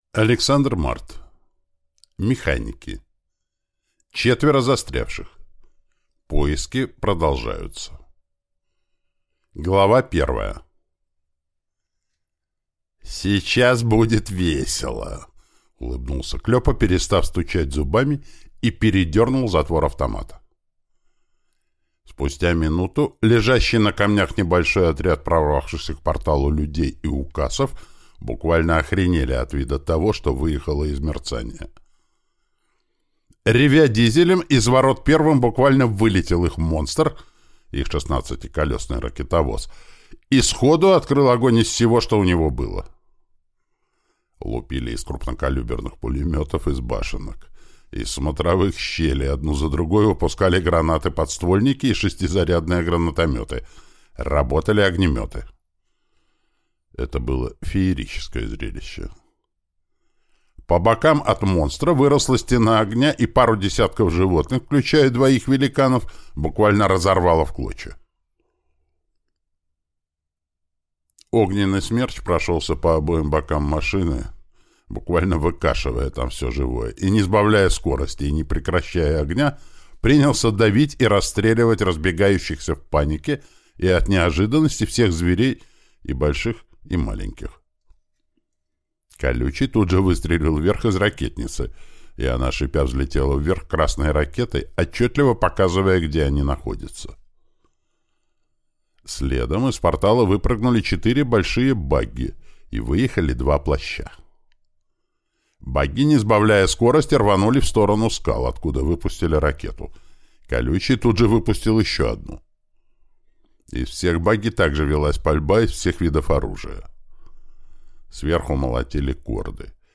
Аудиокнига Механики. Четверо застрявших. Поиски продолжаются | Библиотека аудиокниг